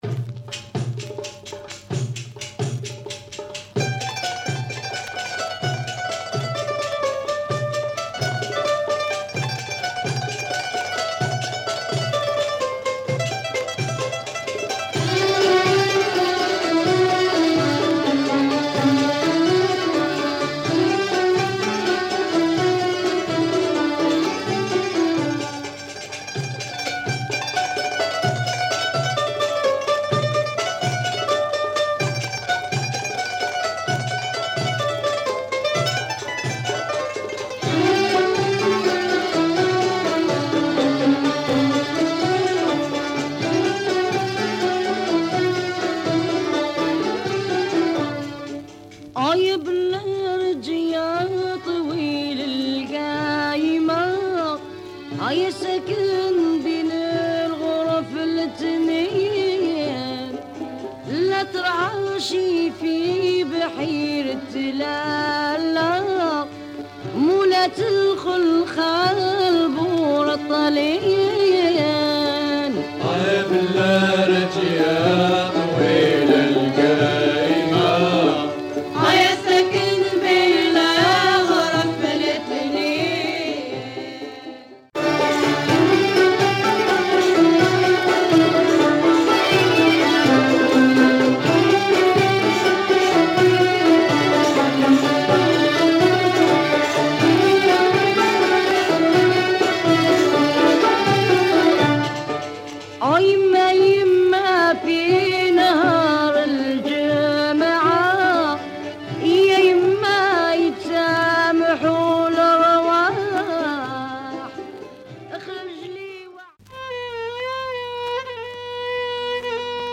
Rare Moroccan / mizrahi music